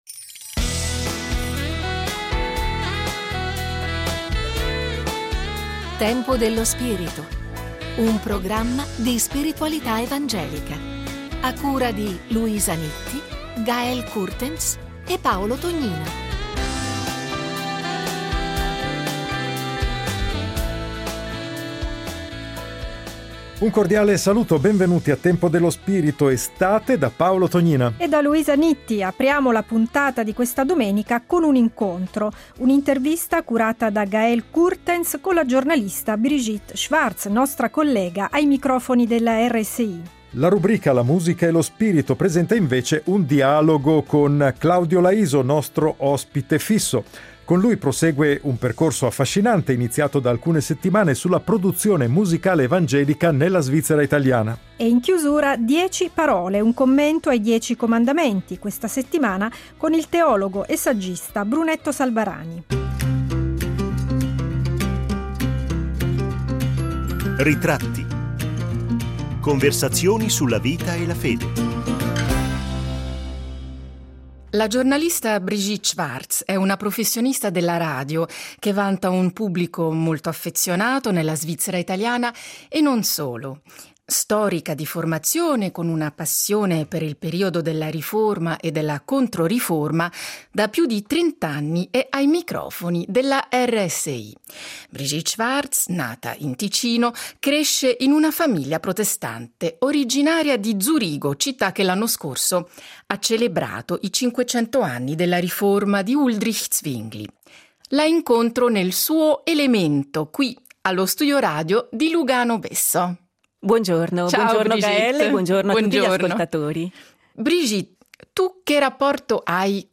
Il rapporto fra fede, spiritualità e scelte di vita quotidiane è il filo rosso che lega queste interviste.